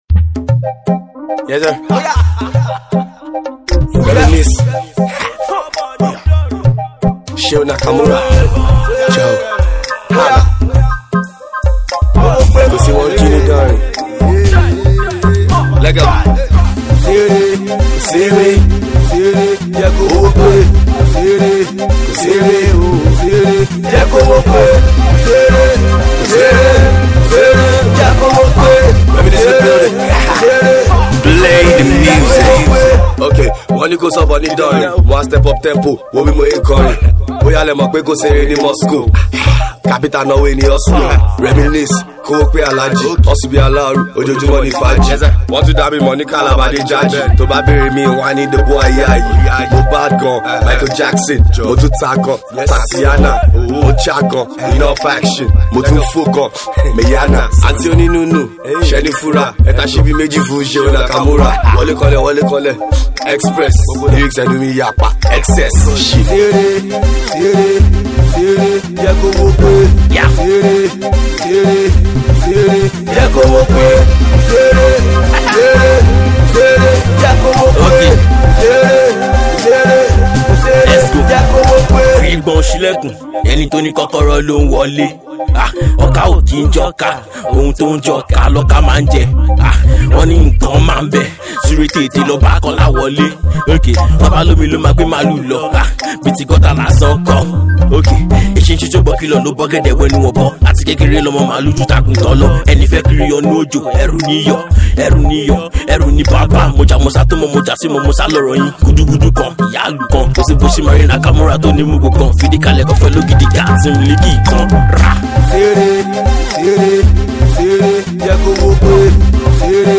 Fun and with a very entertaining progressive production
indigenous Yoruba Rap